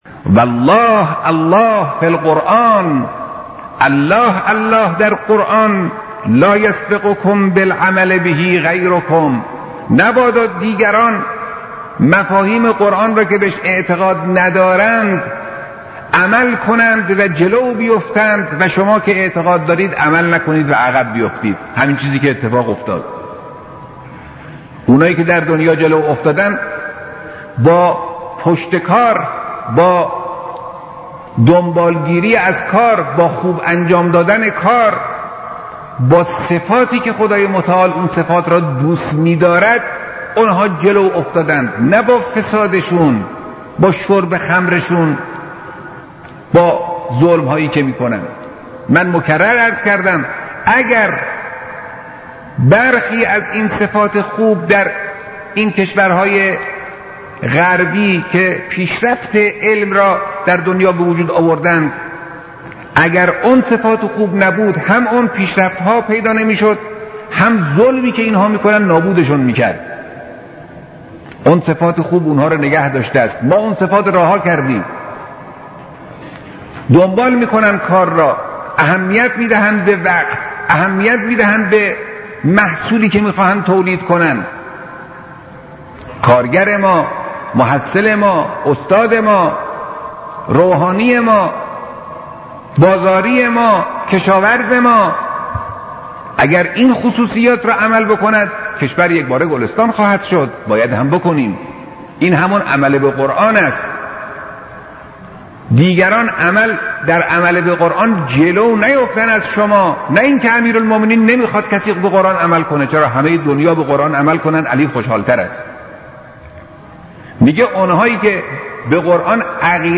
خطبه‌های نمازجمعه 13/اسفند/1372